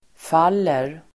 Uttal: [f'al:er]